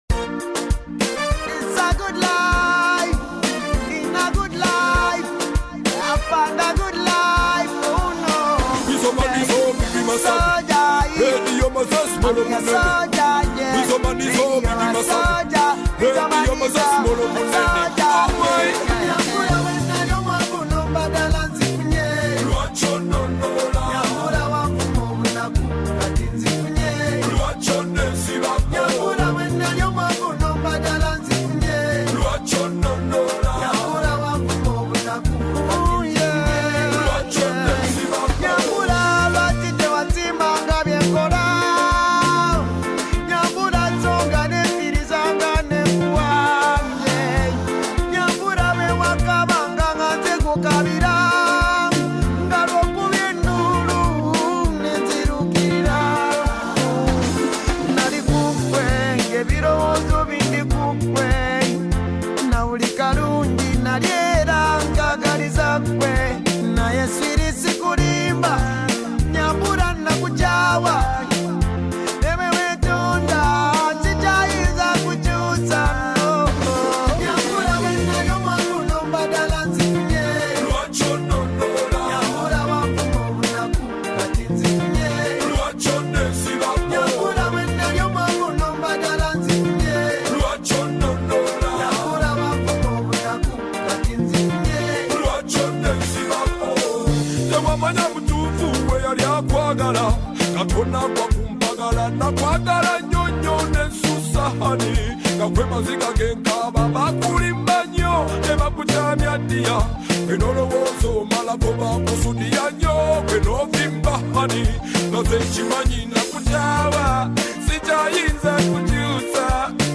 beautiful Afrobeat and Afro-Pop track